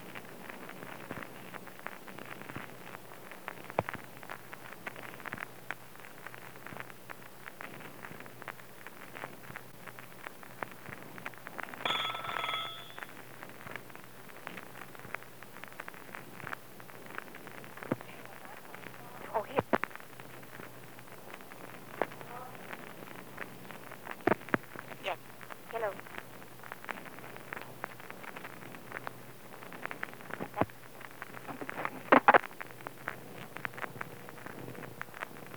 Unidentified conversation
Secret White House Tapes | John F. Kennedy Presidency Unidentified conversation Rewind 10 seconds Play/Pause Fast-forward 10 seconds 0:00 Download audio Previous Meetings: Tape 121/A57.